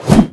shovelswing.wav